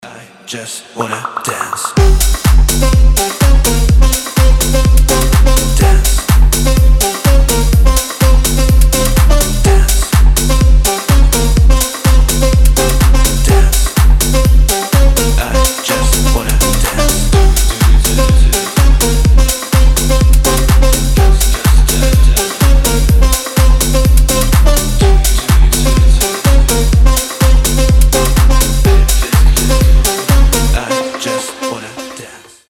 • Качество: 320, Stereo
громкие
зажигательные
future house
electro house